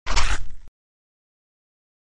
Paradise/sound/weapons/bite.ogg
bite.ogg